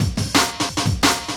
Graffiti 2 175bpm.wav